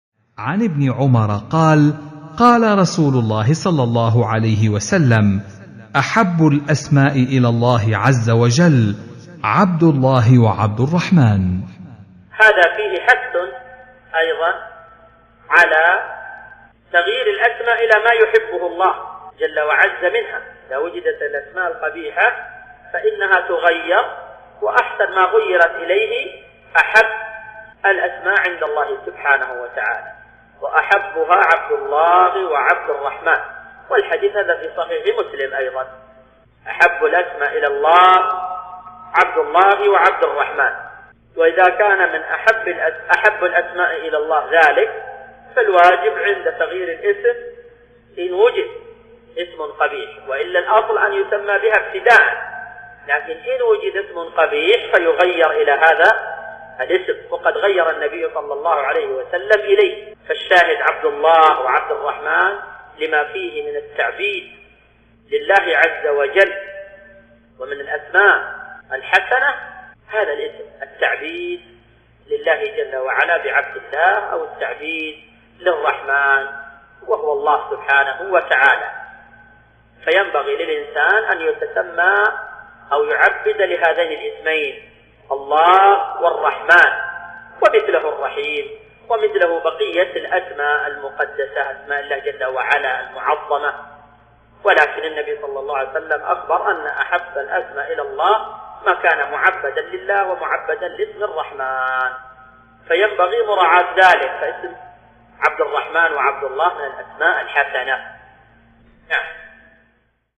شرح حديث أحب الأسماء إلى الله تعالى عبد الله وعبد الرحمن